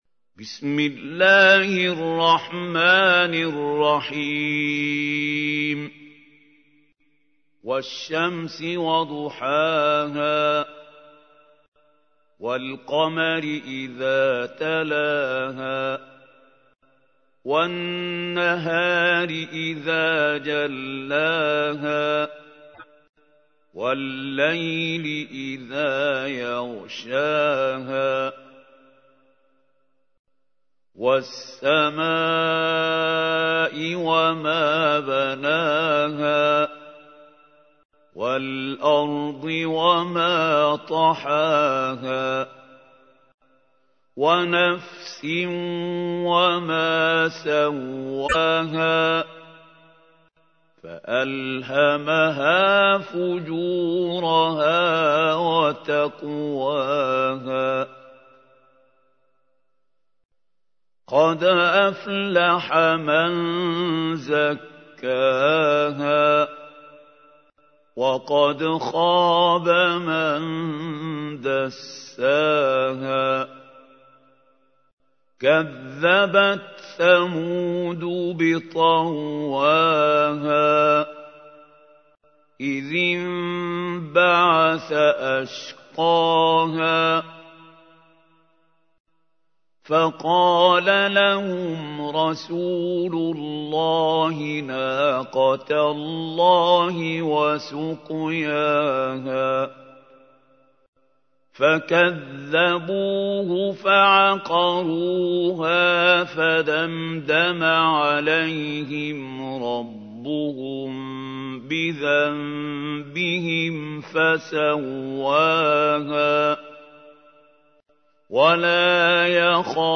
تحميل : 91. سورة الشمس / القارئ محمود خليل الحصري / القرآن الكريم / موقع يا حسين